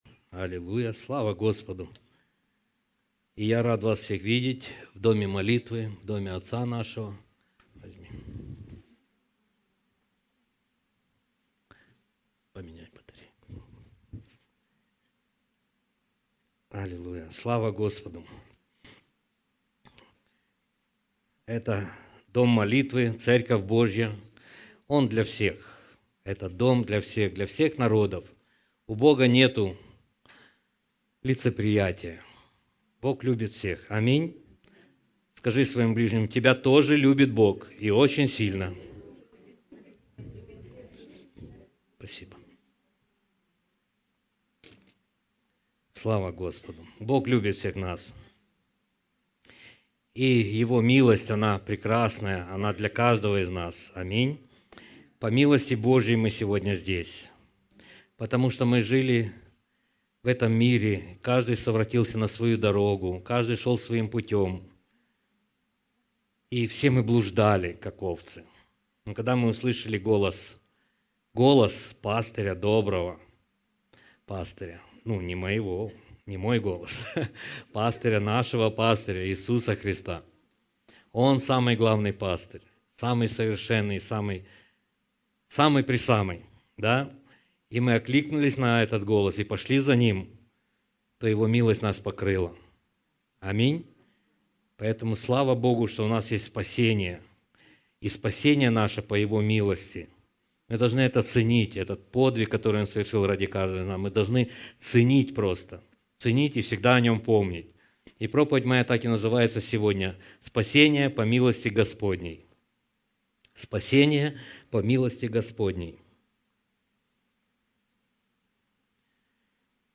По техническим причинам с 9-ой минуты по 11-ю воспроизводится картинка и аудио.